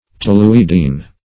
Toluidine \To*lu"i*dine\, n. (Chem.)